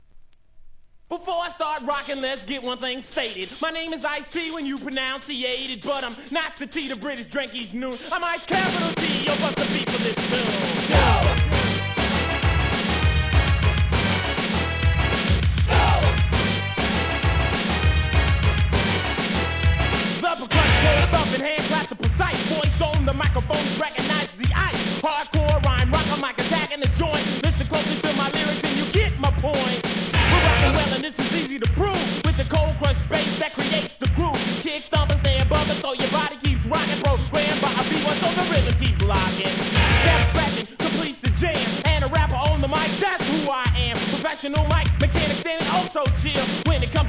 HIP HOP/R&B
1986年、エレクトロOLD SCHOOL!!